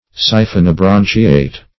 Search Result for " siphonobranchiate" : The Collaborative International Dictionary of English v.0.48: Siphonobranchiate \Si`pho*no*bran"chi*ate\, a. (Zool.)